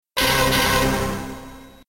terrifying sound effect, the zombies come at you in full force for the first time, so that the game can actually live up to its title.